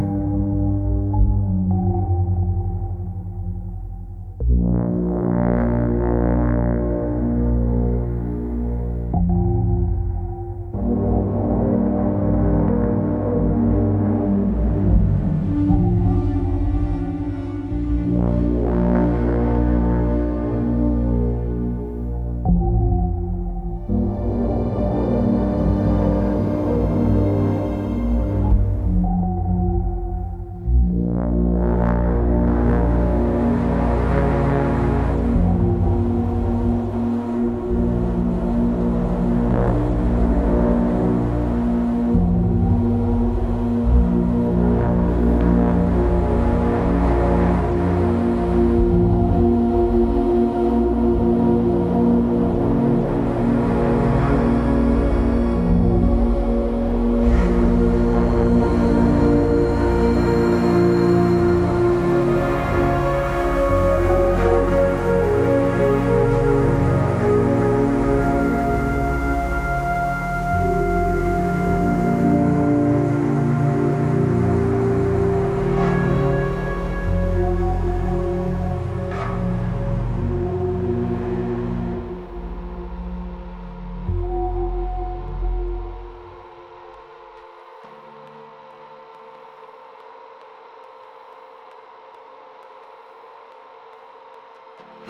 Back Ground Music